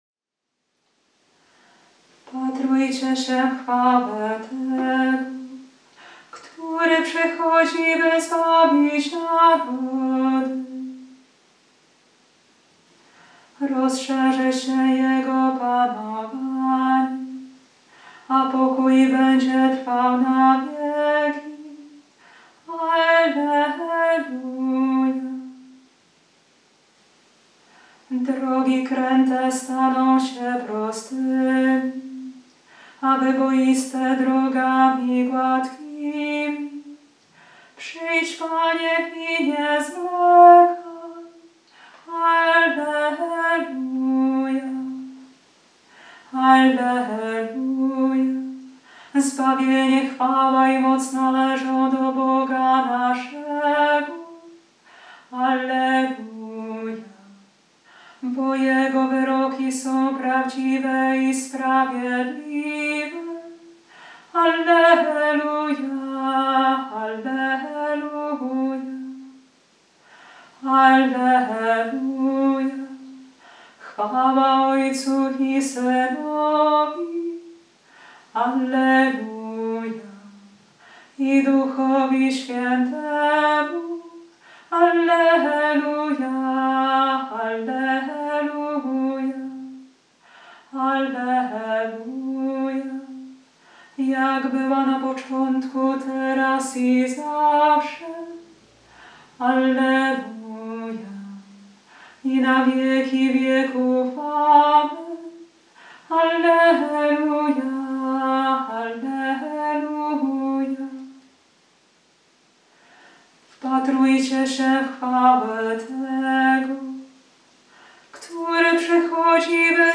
Antyfona ton VI
Ton-6_przyklady-antyfon-z-kantykiem-kjmqn6si.mp3